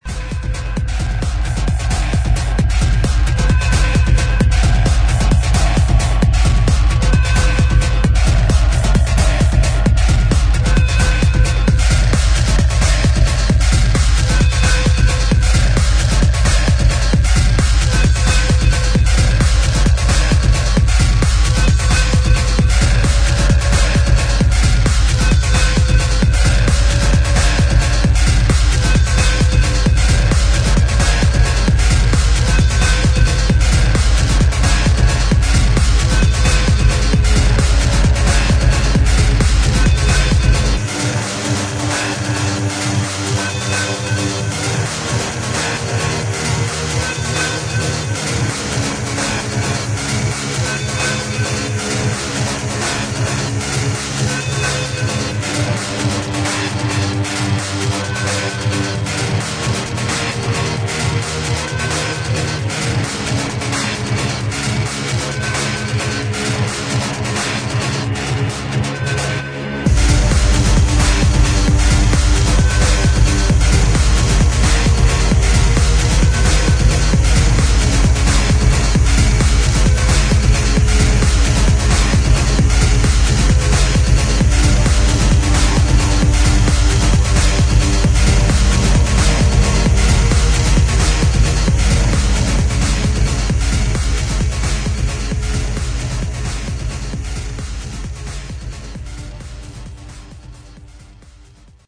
[ TECHNO / INDUSTRIAL ]